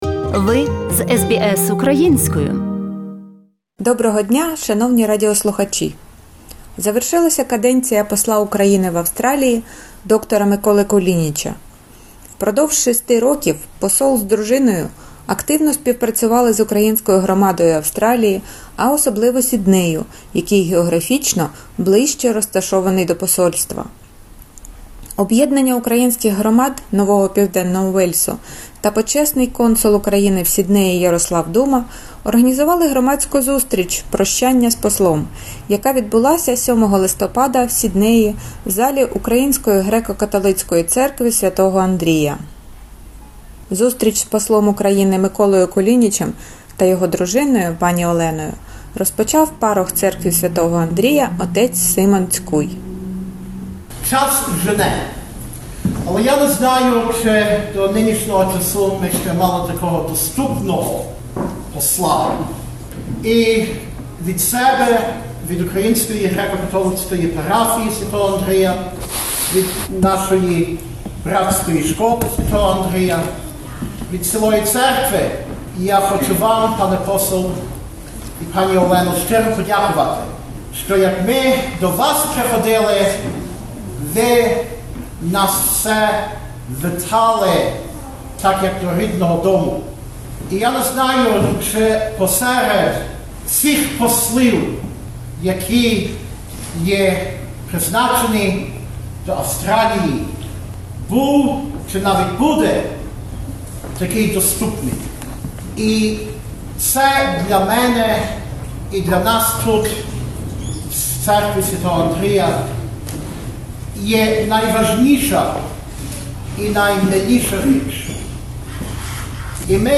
Репортаж з Сіднея про прощальну зустріч посла України в Австралії